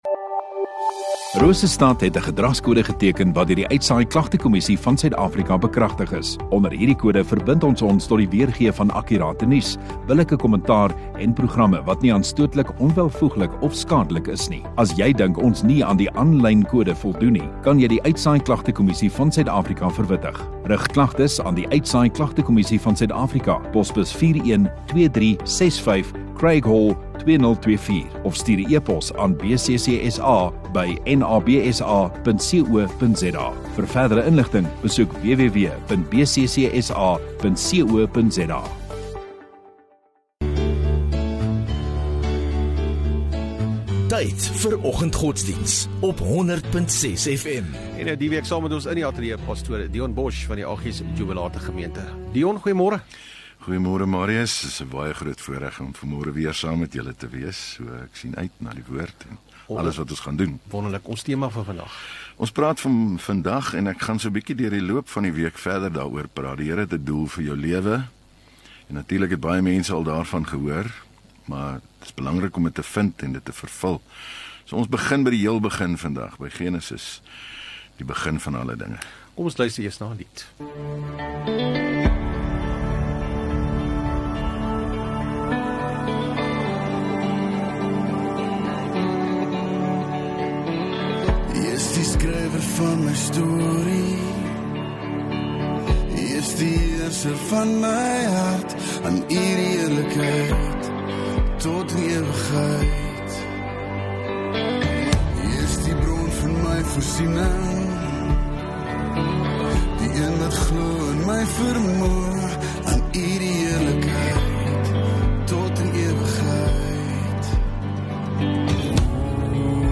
22 Oct Dinsdag Oggenddiens